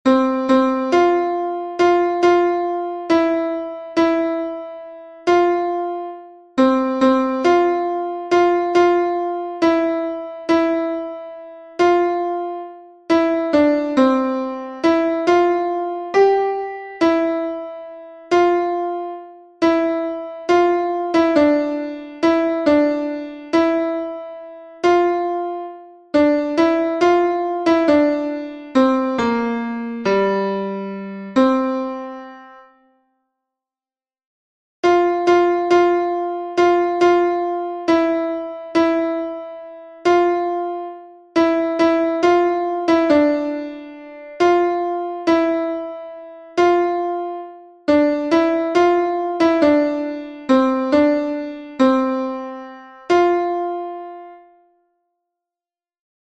Fichier son Alto